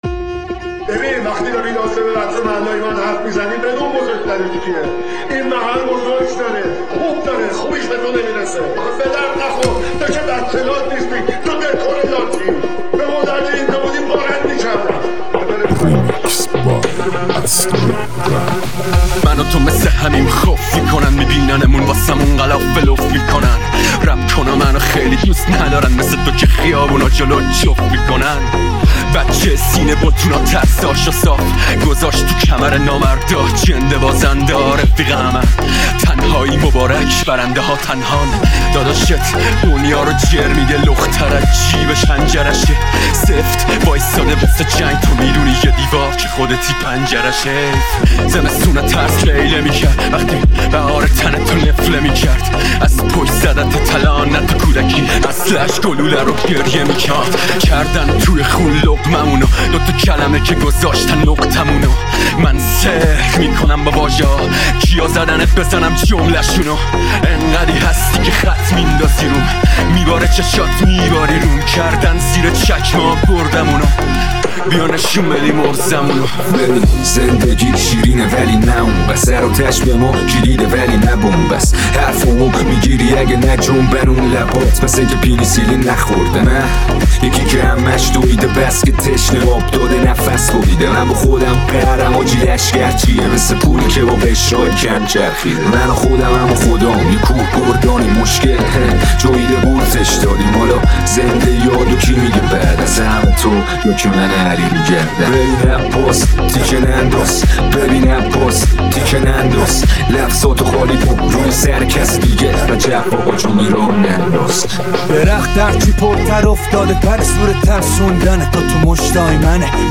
رپ فارسی